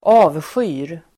Uttal: [²'a:vsjy:r]